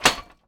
metal_hit_small_07.wav